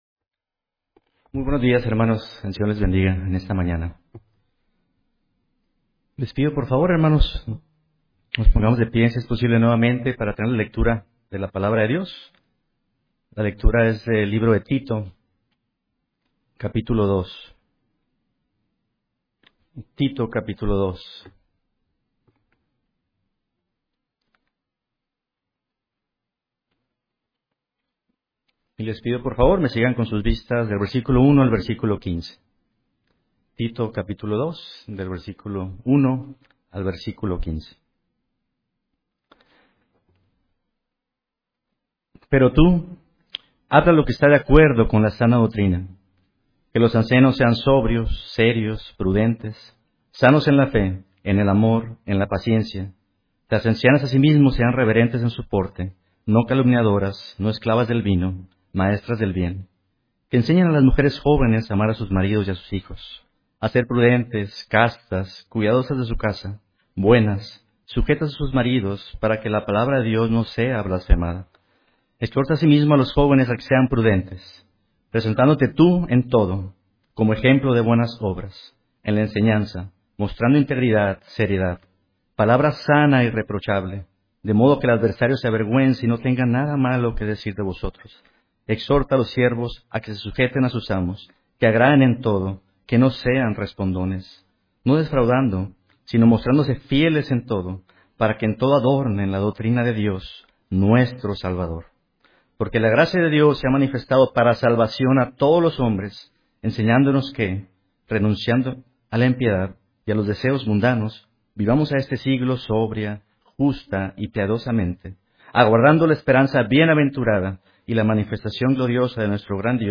Sermón Llamados a vivir la sana doctrina, 2024-07-28